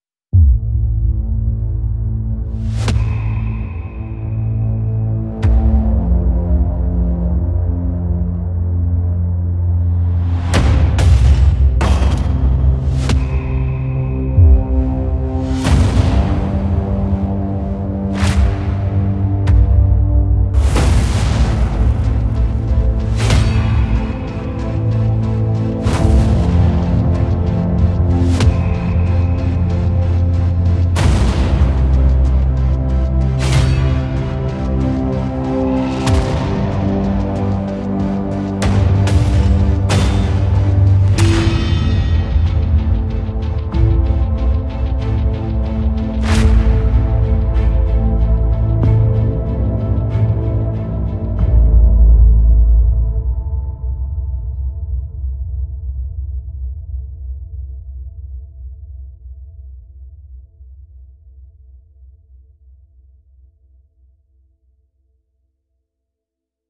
捕捉好莱坞预告片的声音
这些声音都是大规模设计的，主要是非音调、轰鸣声、热门歌曲和嗖嗖声，这在现代预告片和其他电影杰作中最为常见。它们的范围从重金属声音到干净的通风声音，从巨大的史诗般的热门歌曲到柔和、轻柔的水龙头。轰隆隆的声音包括令人心碎的砰砰声和巨大的、令人痛苦的倒下声。
有什么 - 150 次繁荣、点击和嗖嗖声 - 280 MB 高质量内容